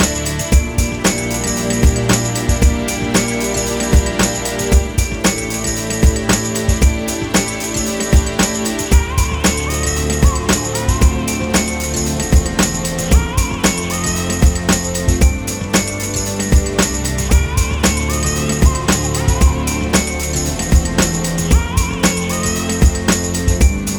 no Backing Vocals Dance 5:12 Buy £1.50